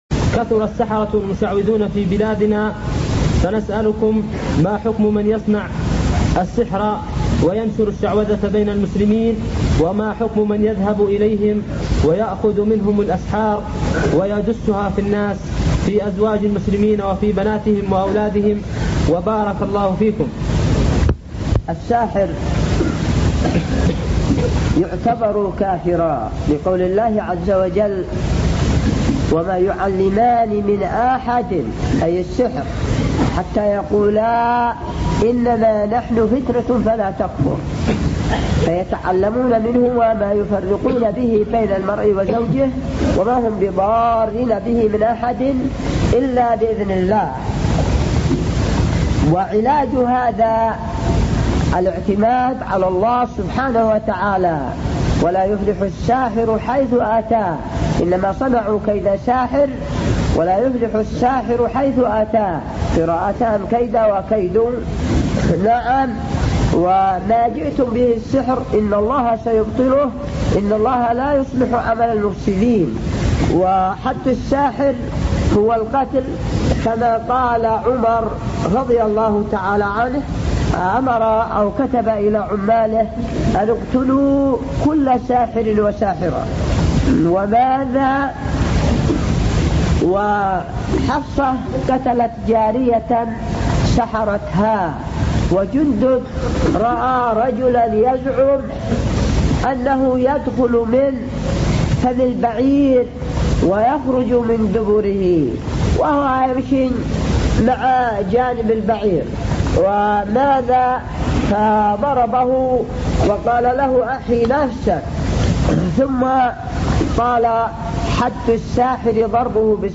------------ من شريط : ( أسئلة شباب ليبيا ) .